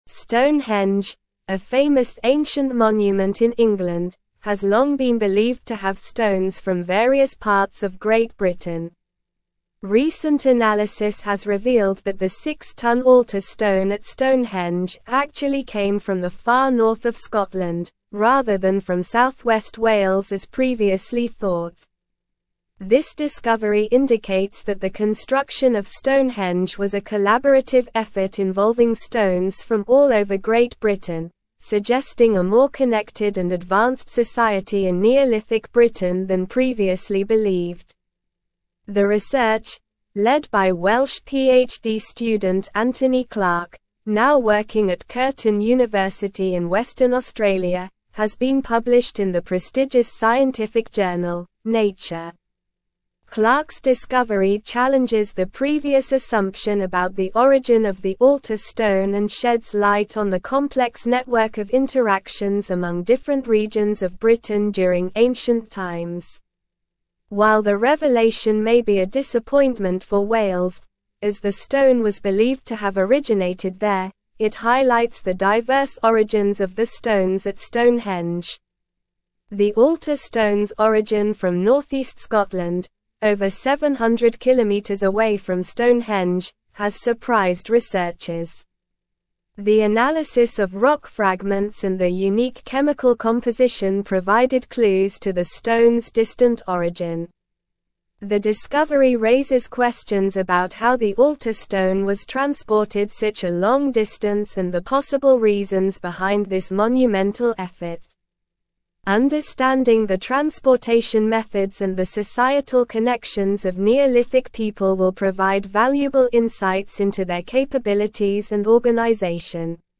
Slow
English-Newsroom-Advanced-SLOW-Reading-Stonehenge-Altar-Stone-Originates-from-Scotland-Not-Wales.mp3